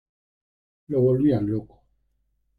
Read more Adj Noun Noun Frequency A1 Hyphenated as lo‧co Pronounced as (IPA) /ˈloko/ Etymology Inherited from Old Spanish loco Cognate with Portuguese louco Cognate with Sicilian loccu In summary Uncertain.